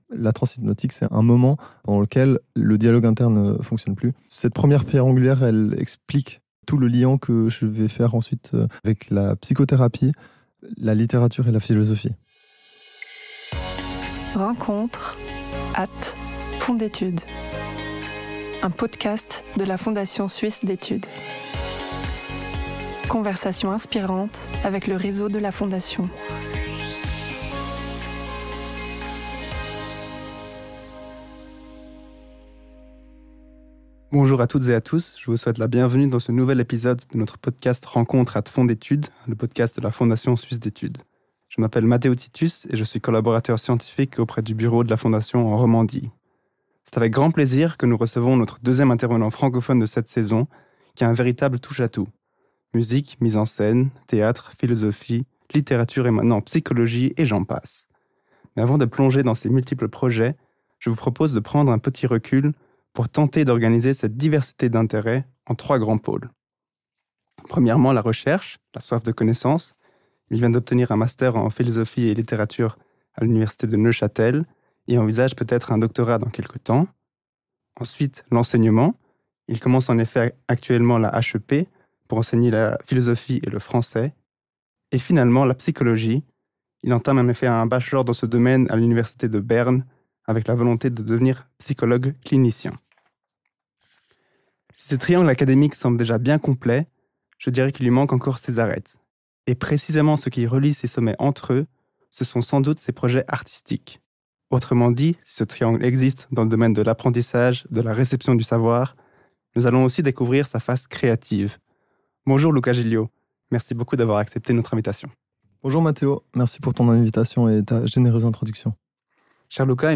Ce qui relie ces horizons, c’est une même fascination pour l’intersubjectivité, là où se tisse notre rapport au monde et aux autres. Une conversation où les concepts rencontrent la création, où les apprentissages préparent des éclosions généreuses.